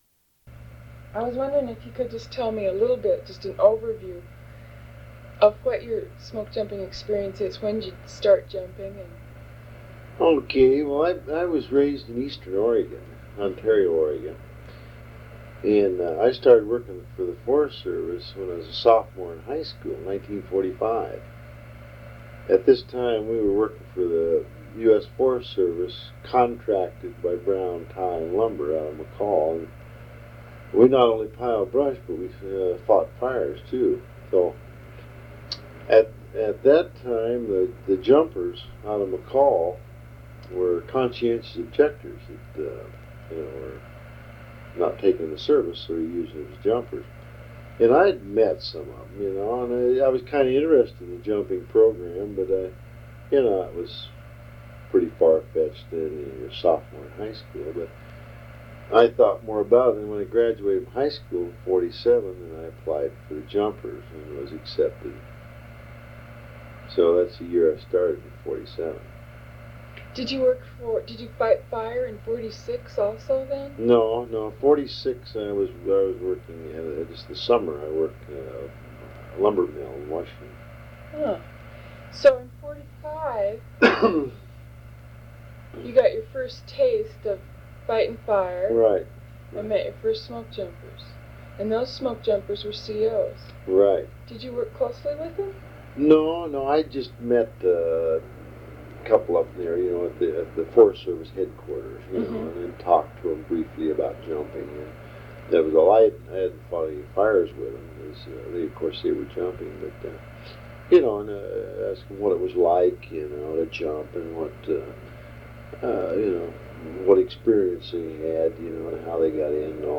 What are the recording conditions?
1 sound cassette (68 min.) : analog